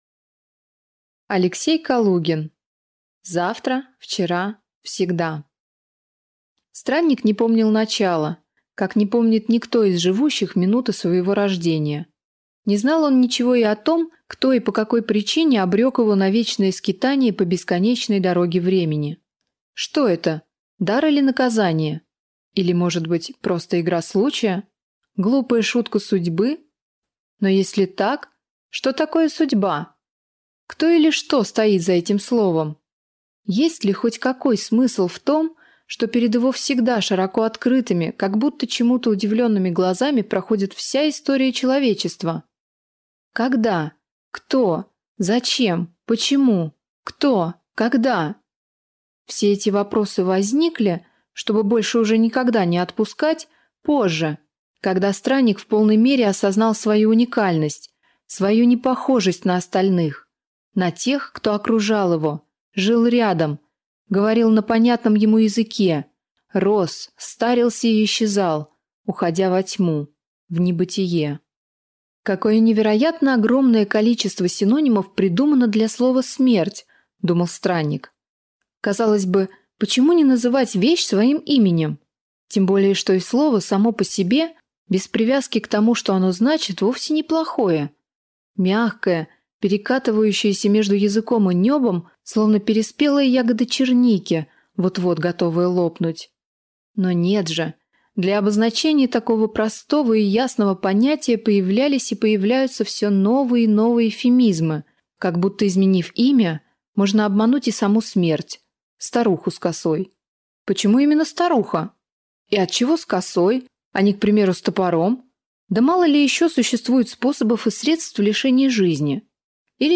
Аудиокнига Завтра, вчера, всегда | Библиотека аудиокниг
Прослушать и бесплатно скачать фрагмент аудиокниги